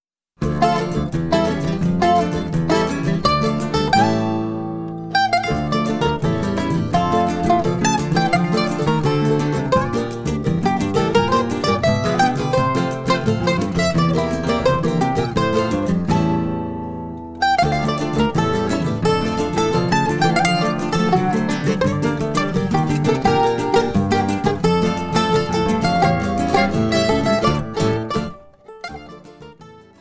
bandolim